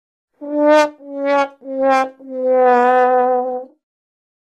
Sad Trombone Wah Wah Wah Fail